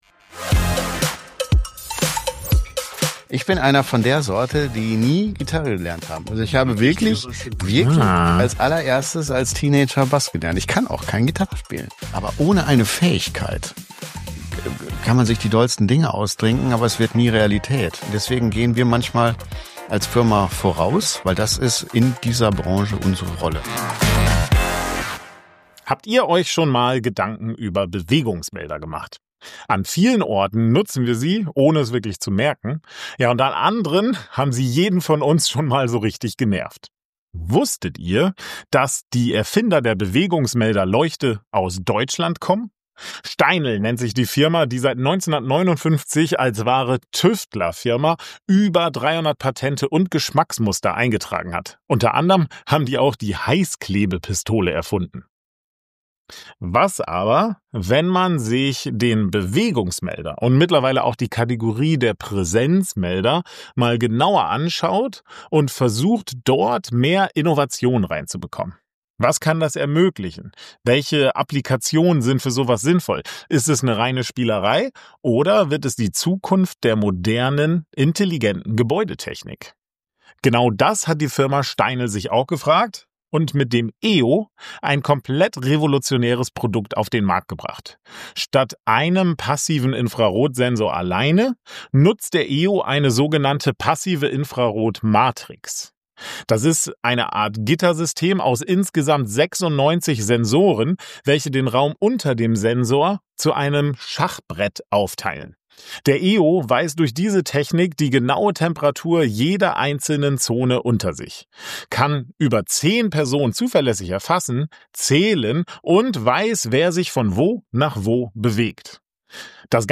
Revolution in der Gebäudetechnik. Steinel EO Smart Space Sensor ~ Stecker Checker Expertentalk Podcast